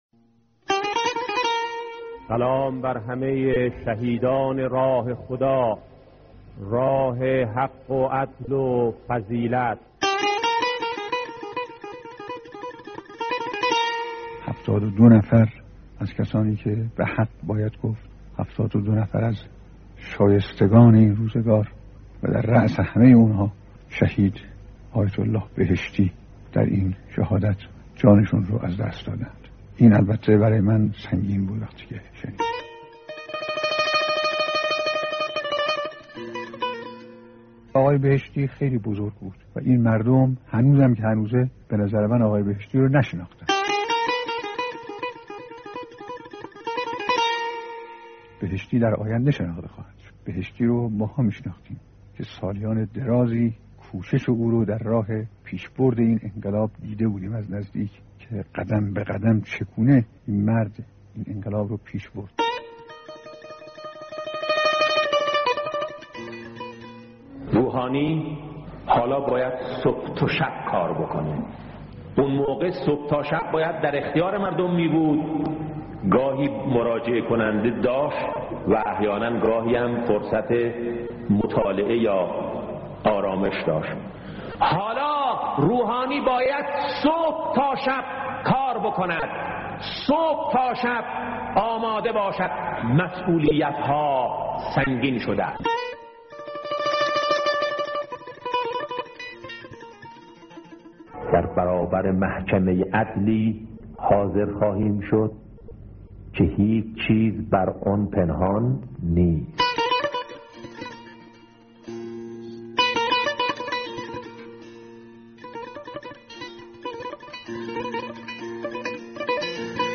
صوت کامل بیانات